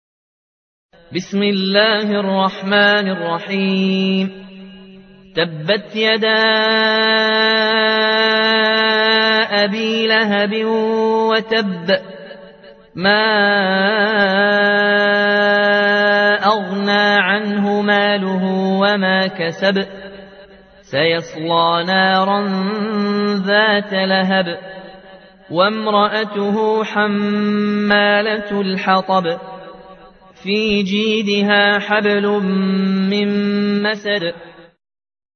سورة المسد | القارئ